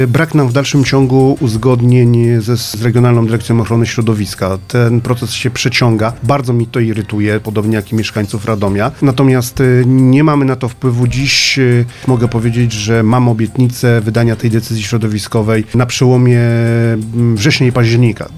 Oczyszczanie stawów kolmatacyjnych przy zalewie na Borkach to część z prac związanych z programem Life, jaki na terenie miasta realizują Wodociągi Miejskie – mówi prezydent Radosław Witkowski.